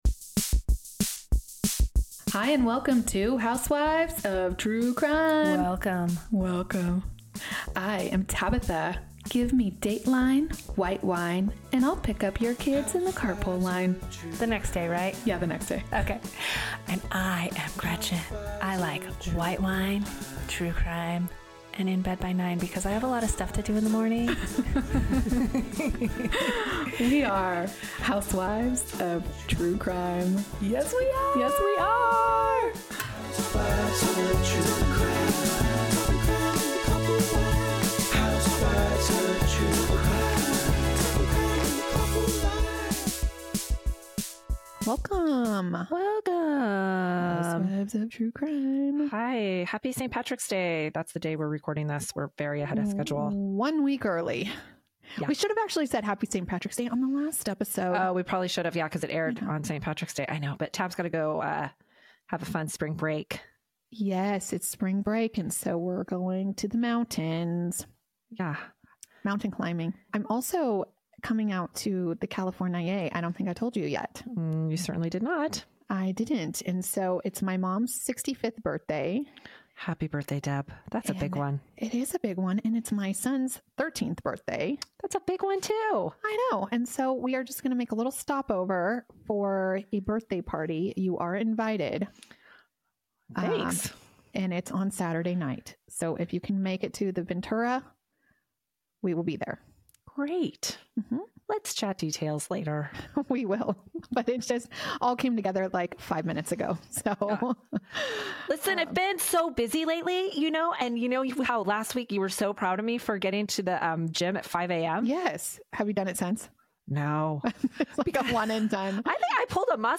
Every week one of the two hosts will tell a true crime favorite while the other host asks the questions everyone wants to know. We are talking crime in the carpool line.